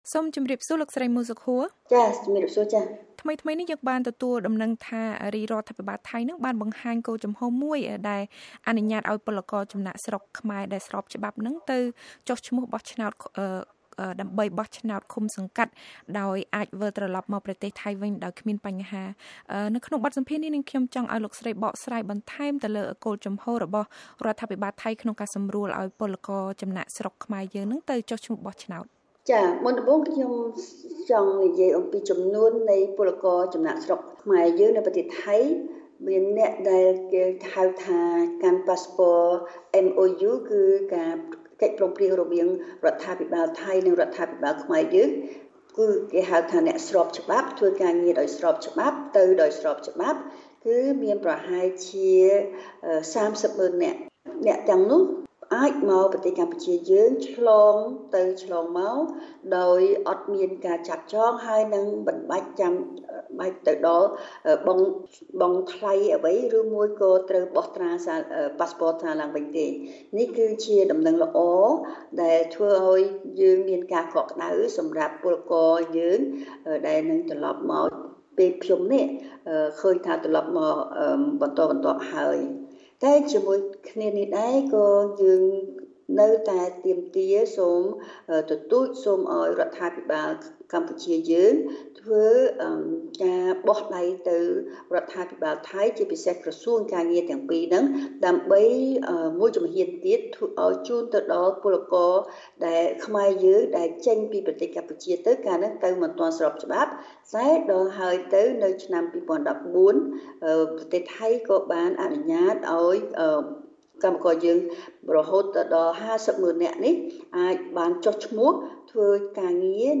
បទសម្ភាសន៍ VOA៖ ពលករខ្មែរនៅថៃស្របច្បាប់អាចមកចុះឈ្មោះបោះឆ្នោតដោយមានការសម្រួលពីរដ្ឋាភិបាលថៃ